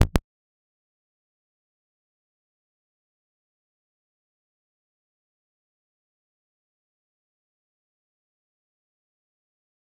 G_Kalimba-D0-f.wav